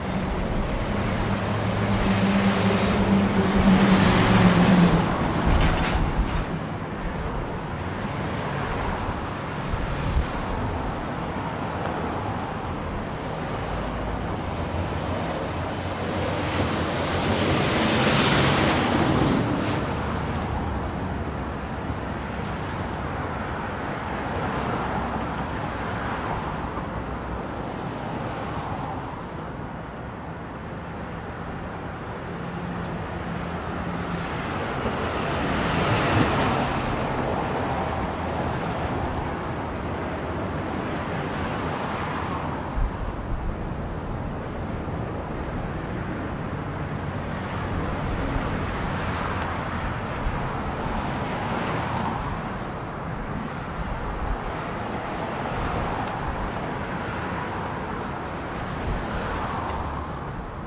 半挂牵引车在高速公路上行驶
Tag: 半挂车 半挂牵引车 运输 车辆 高速公路